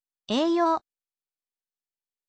ei you